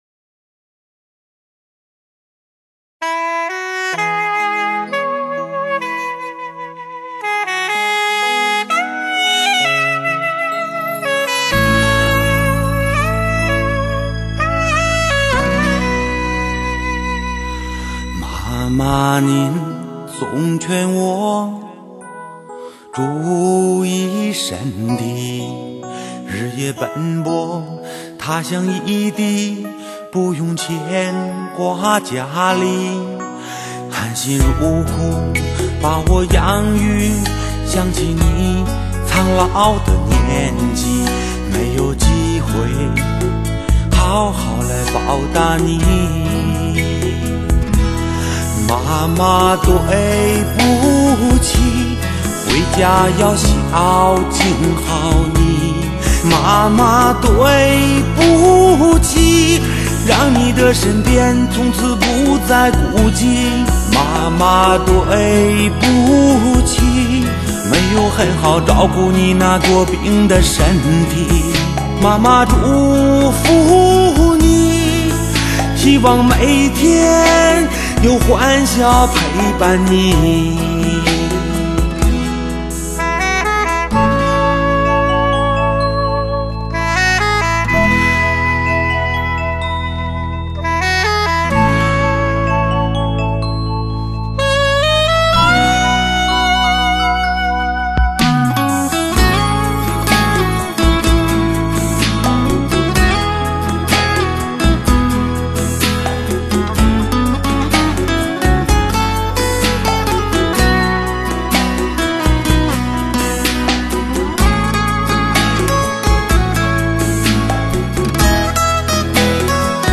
吉他
萨克斯